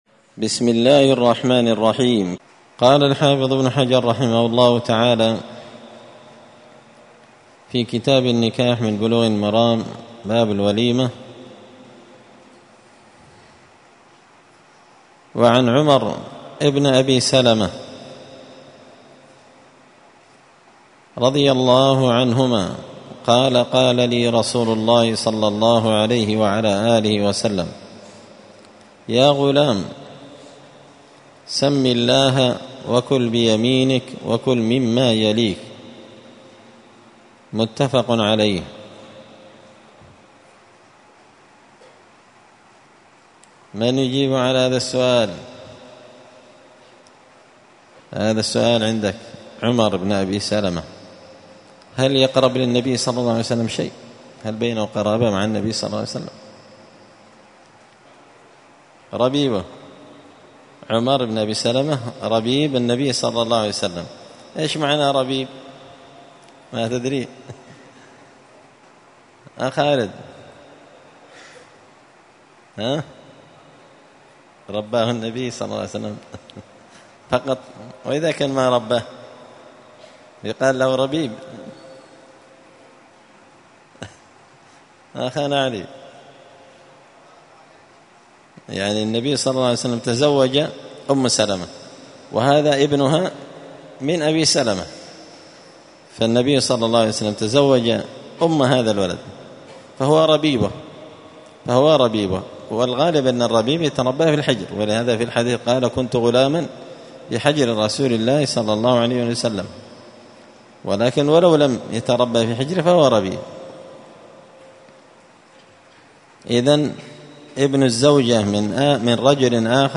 الثلاثاء 16 ربيع الثاني 1445 هــــ | 3كتاب النكاح، الدروس، سبل السلام شرح بلوغ المرام لابن الأمير الصنعاني | شارك بتعليقك | 83 المشاهدات
مسجد الفرقان_قشن_المهرة_اليمن